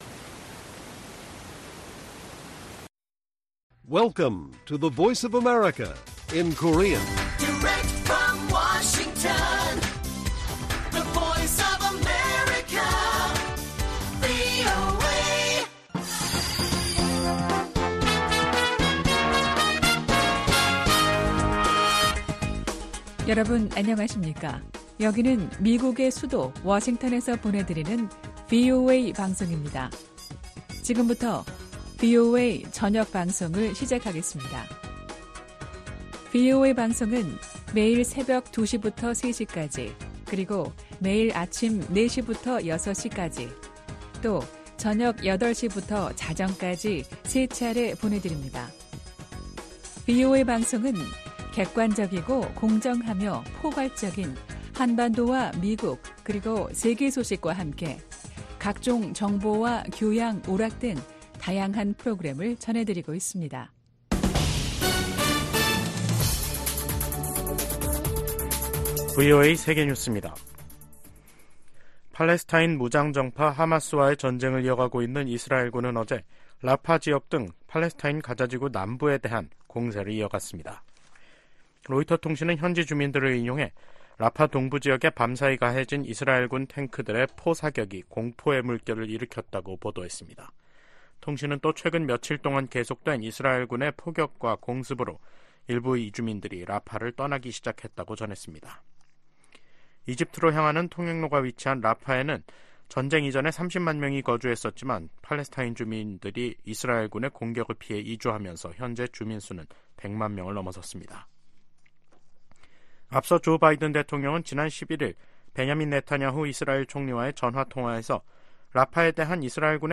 VOA 한국어 간판 뉴스 프로그램 '뉴스 투데이', 2024년 2월 14일 1부 방송입니다. 북한이 또 동해상으로 순항미사일을 여러 발 발사했습니다. 미 국무부는 북한이 정치적 결단만 있으면 언제든 7차 핵실험을 감행할 가능성이 있는 것으로 판단하고 있다고 밝혔습니다. 백악관이 북한의 지속적인 첨단 무기 개발 노력의 심각성을 지적하며 동맹 관계의 중요성을 강조했습니다.